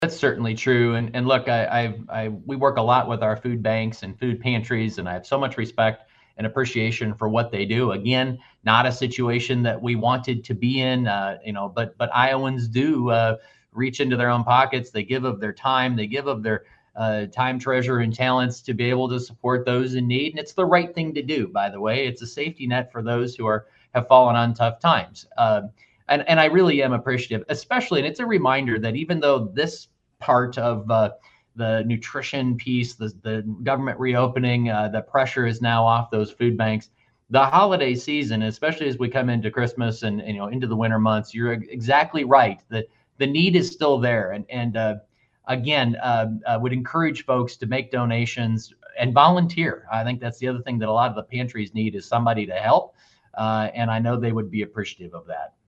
It was a major topic when I sat down for my monthly conversation with Iowa Secretary of Agriculture Mike Naig.